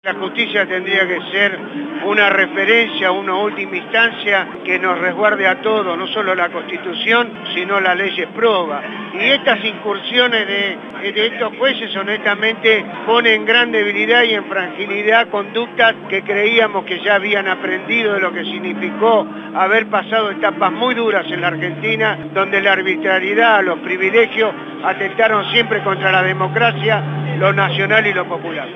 En ésta radio abierta se pudieron escuchar las voces de…
El diputado nacional Juan Carlos Dante Gullo